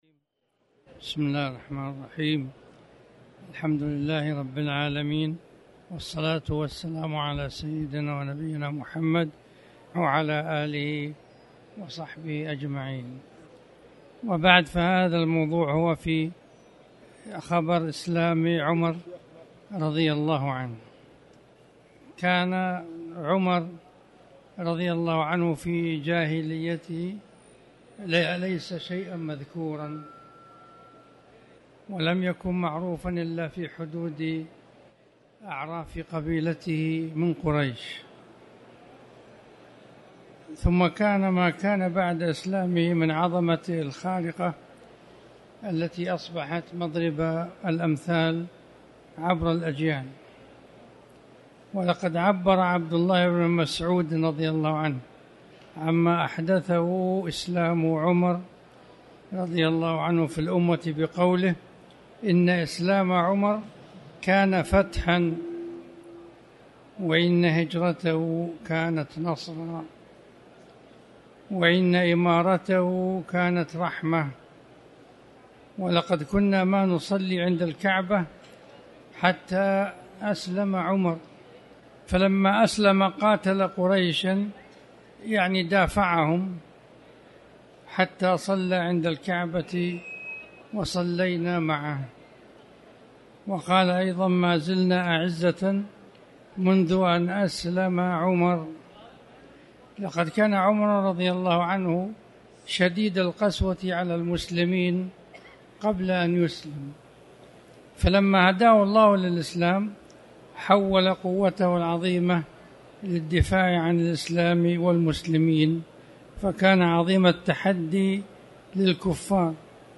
تاريخ النشر ٢١ ذو القعدة ١٤٣٨ هـ المكان: المسجد الحرام الشيخ